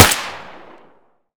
sako95_suppressed_fire1.wav